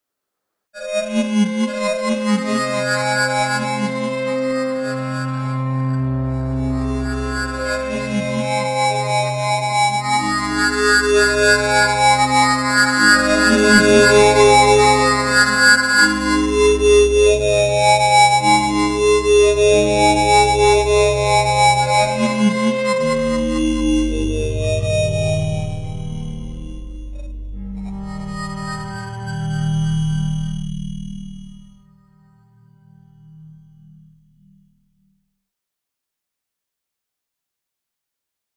SciFi Sounds » Spaceship Alarm
描述：Made with Xfer Records Serum, SonicCharge Synplant and Izotope Iris 2
标签： Synthethic Futuristic Electronic Synth Alien Fiction Digital Scifi Noise
声道立体声